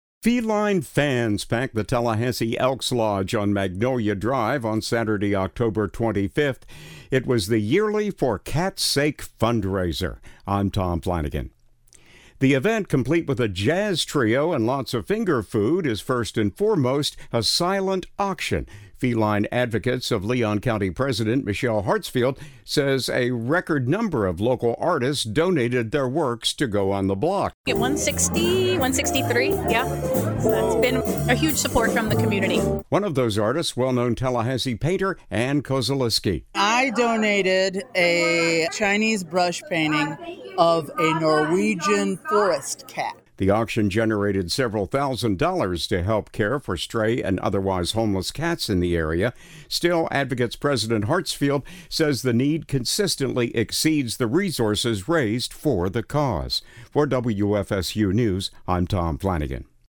Feline fans packed the Tallahassee Elks Lodge on Magnolia Drive Saturday.
The event, complete with a jazz trio and lots of finger food, is first and foremost a silent auction.